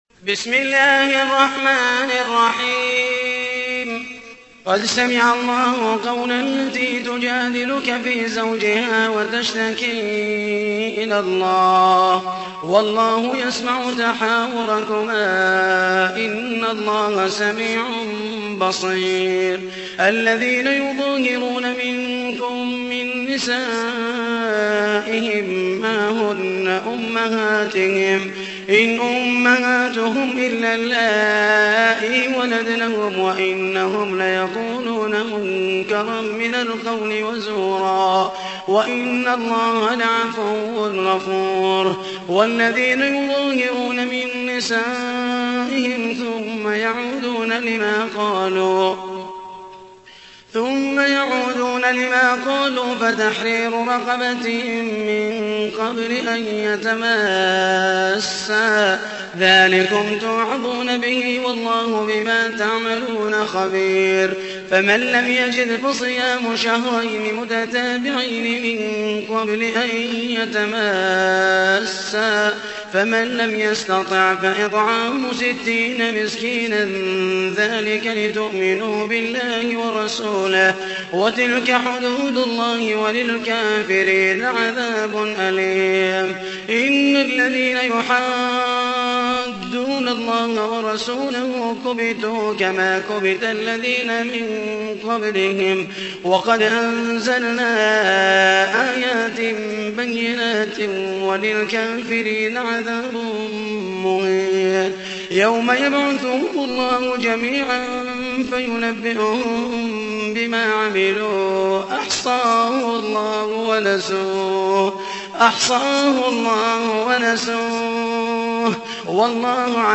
تحميل : 58. سورة المجادلة / القارئ محمد المحيسني / القرآن الكريم / موقع يا حسين